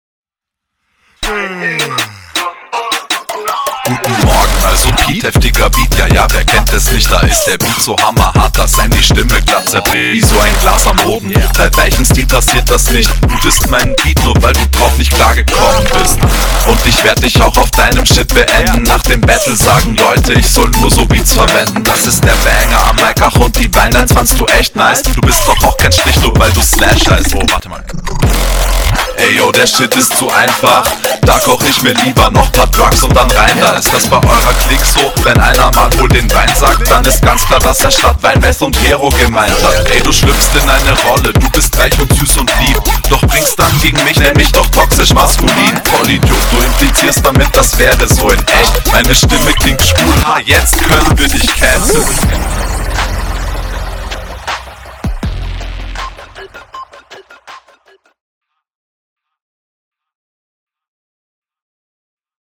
Flow: sicher aber meiner Meinung nach etwas zu monoton, da punktet dein Gegner an dieser …